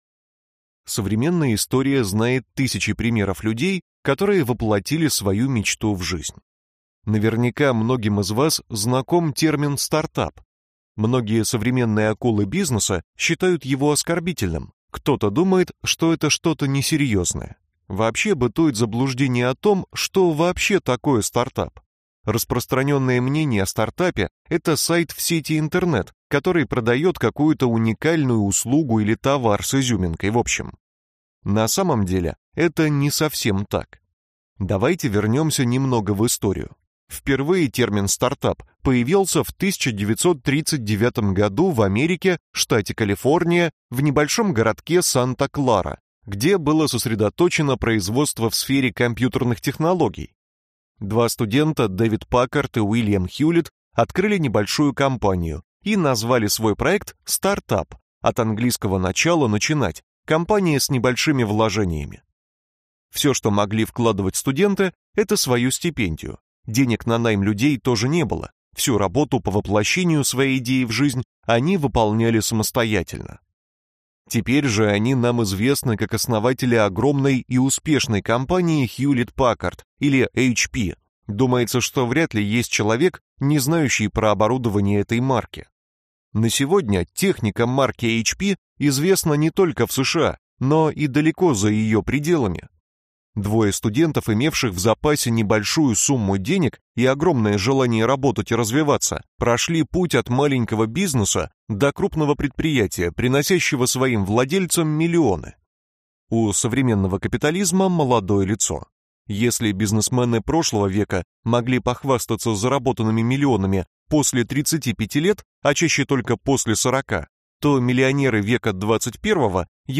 Аудиокнига Миллионер без правил. Как разбогатеть в XXI веке | Библиотека аудиокниг